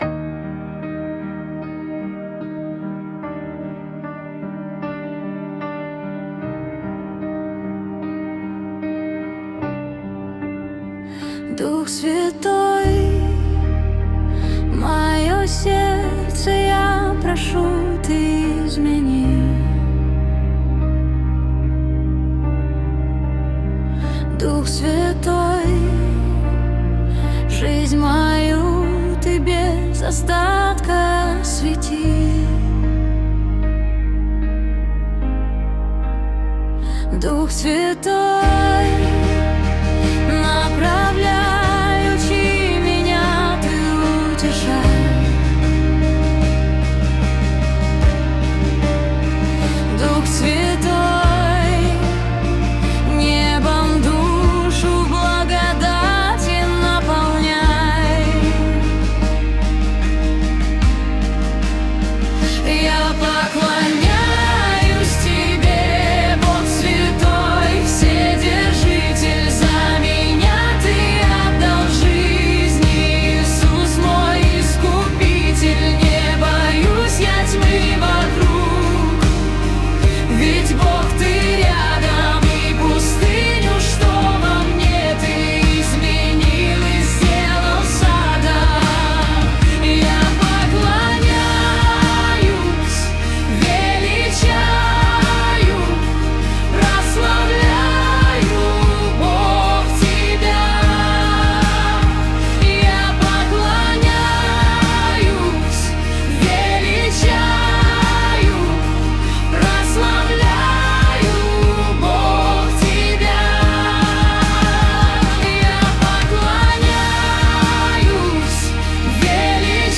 песня ai
25 просмотров 93 прослушивания 4 скачивания BPM: 75